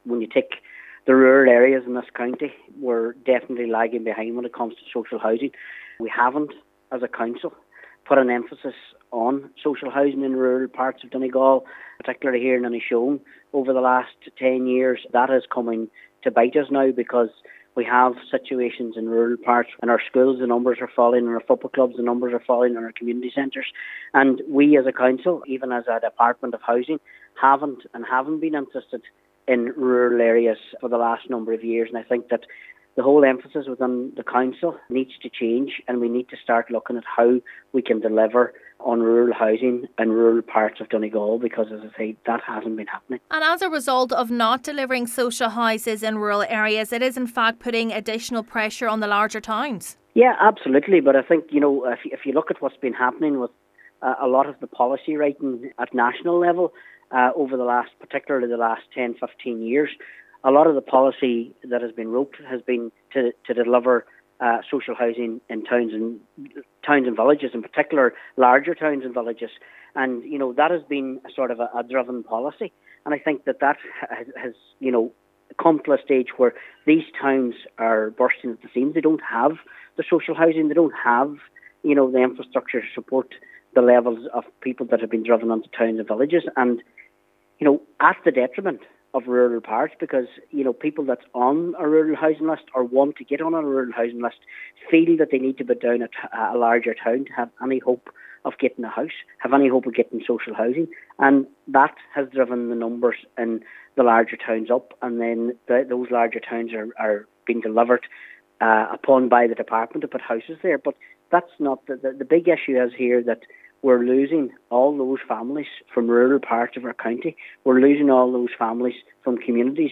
Councillor McDermott says the Council needs to review policies to ensure the delivery of dwellings back into rural areas: